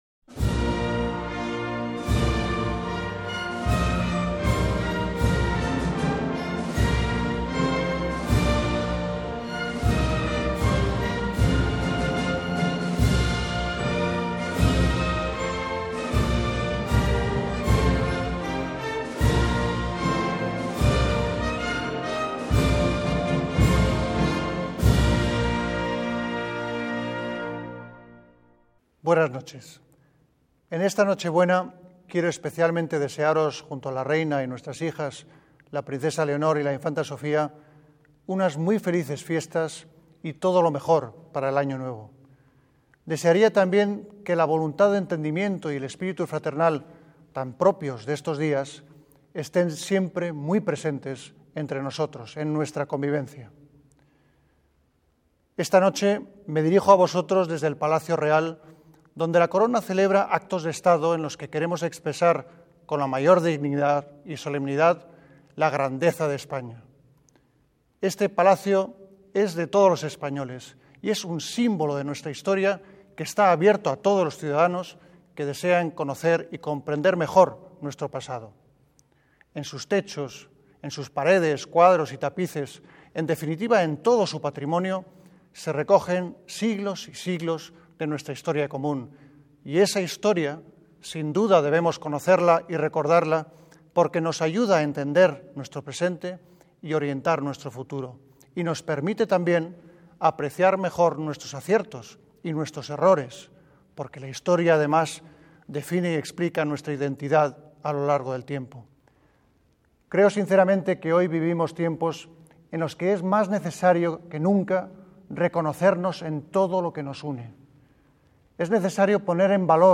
Detalle Discurso - Mensaje de Navidad de Su Majestad el Rey